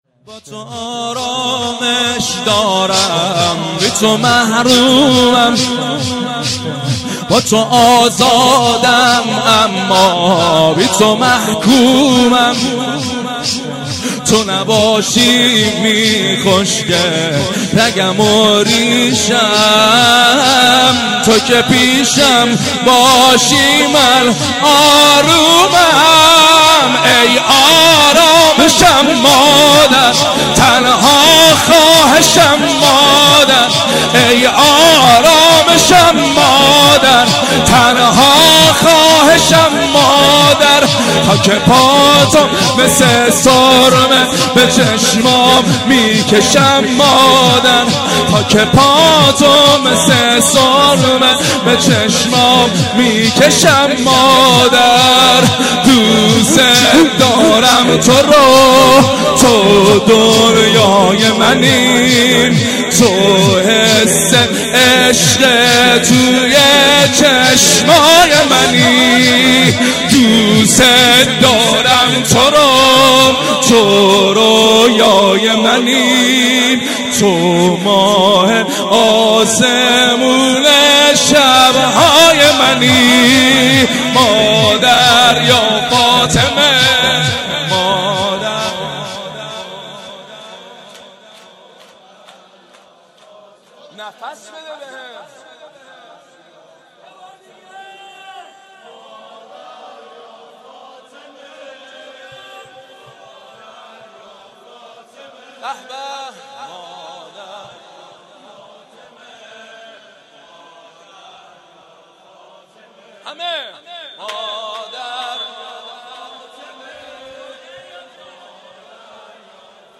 شب سوم ویژه برنامه فاطمیه دوم ۱۴۳۹